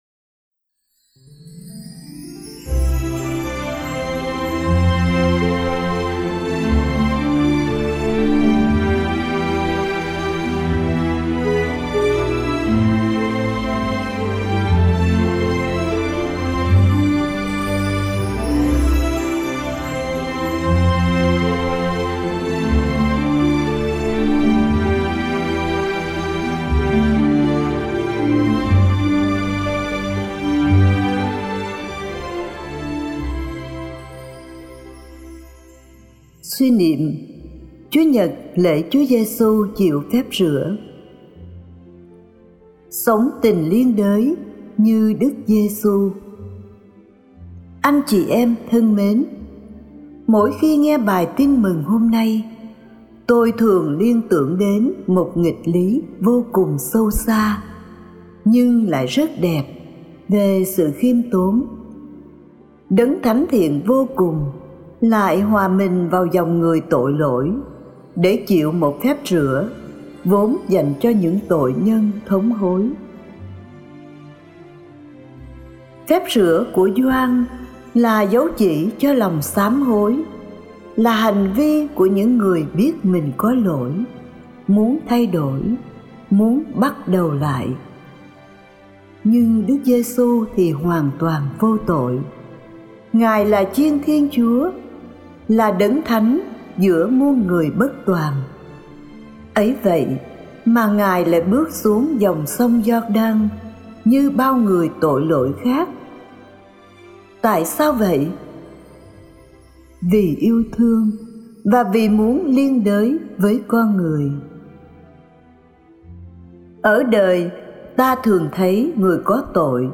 Chủ đề: Sống tình liên đới như Đức Giê-su (Suy niệm Chúa nhật lễ Chúa chịu Phép Rửa A - 2026)